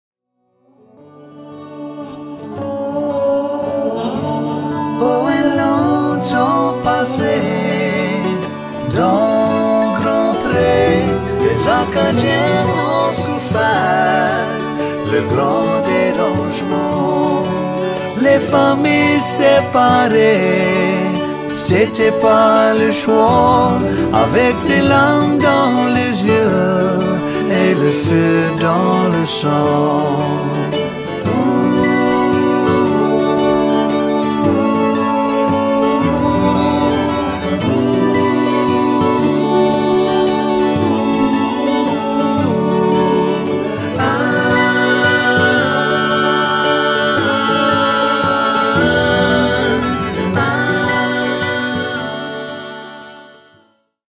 accordions and vocals and acoustic guitar
bass
petite fer